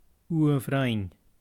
Scottish Gaelic name Eige Pronunciation [ˈekʲə]